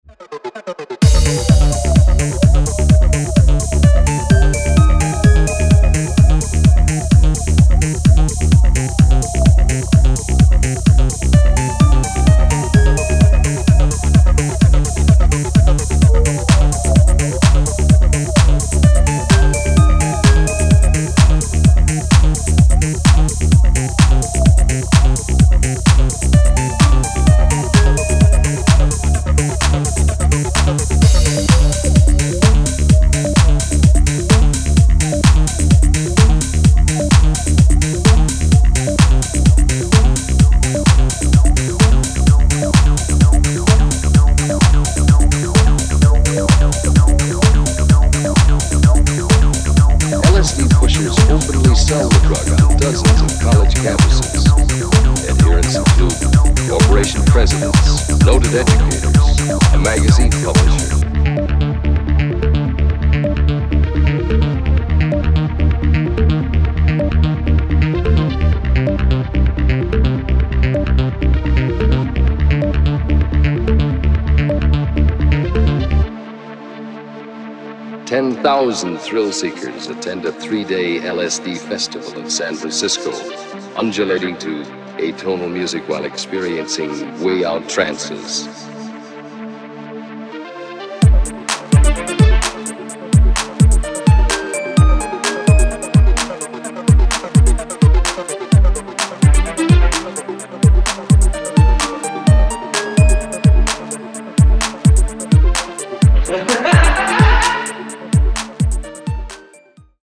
Electro Techno Acid Wave Breaks